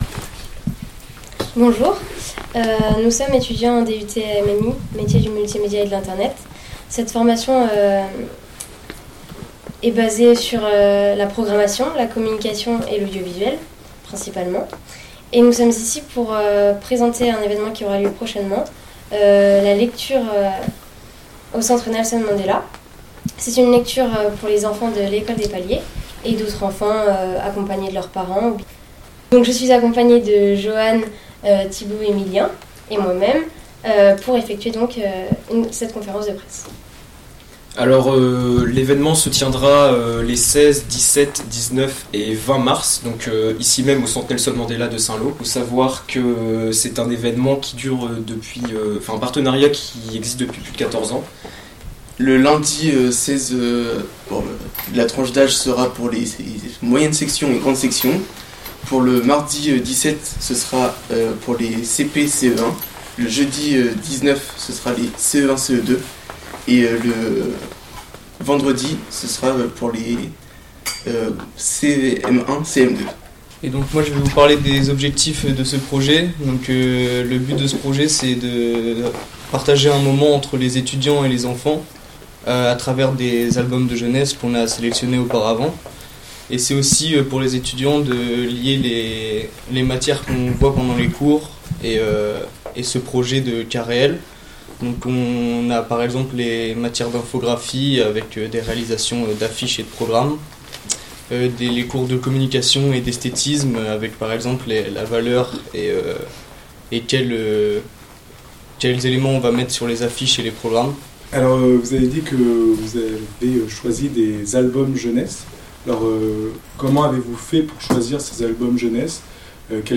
Lors de la répétition au Centre Nelson Mandela, les étudiants présentent le projet lecture avec l'école des Paliers, aux journalistes.